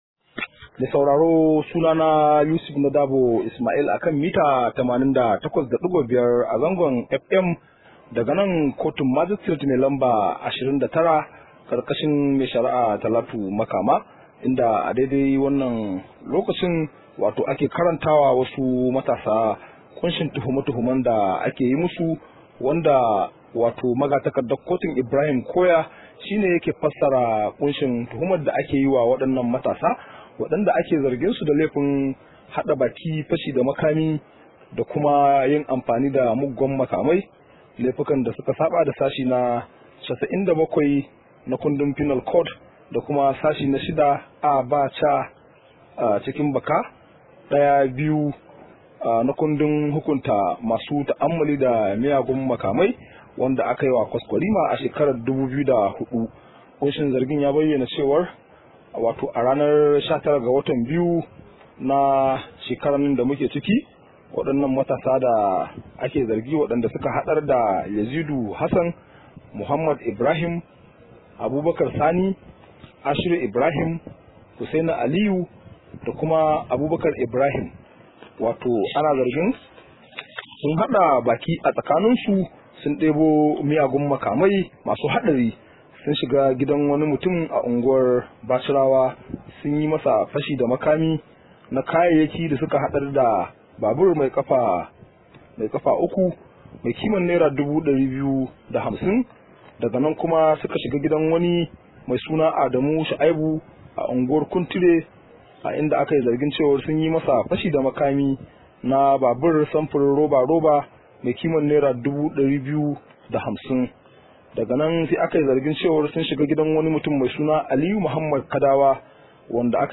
Rahoto: Ana zargin matasa 6 da fashi da makami